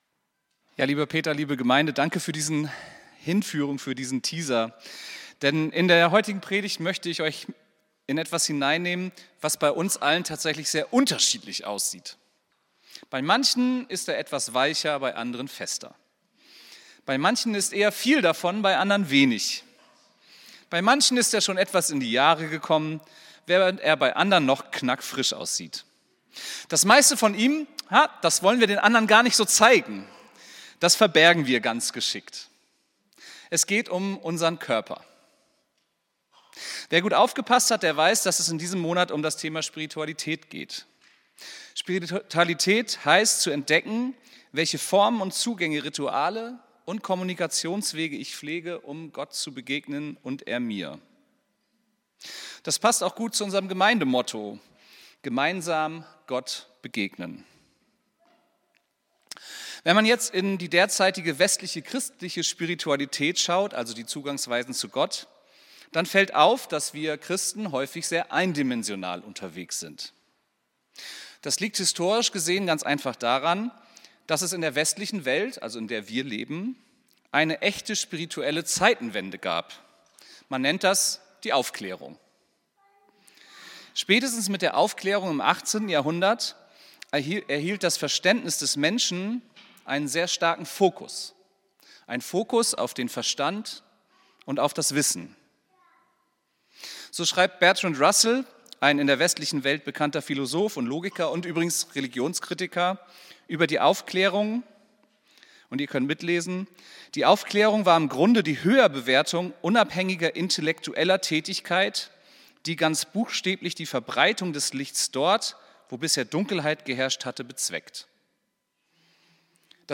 Predigt vom 16.02.2025